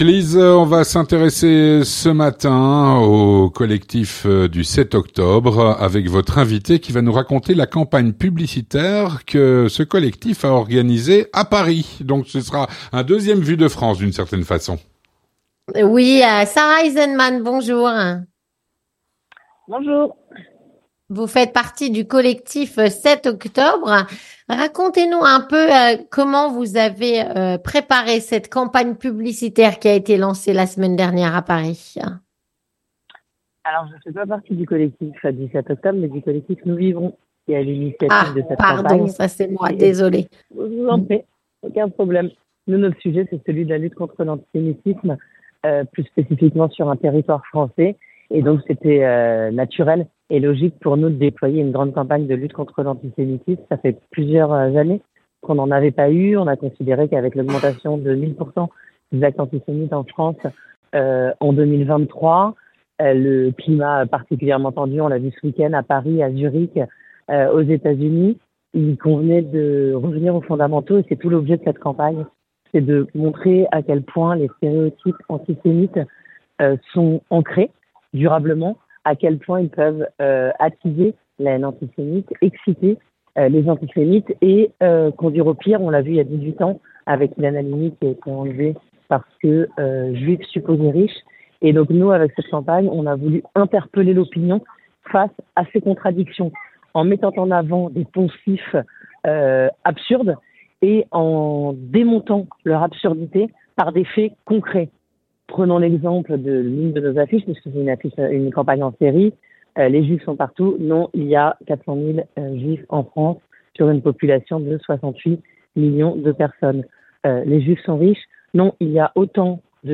Rencontre - Le collectif “Nous vivrons” nous raconte la campagne publicitaire qu’ils ont organisé à Paris.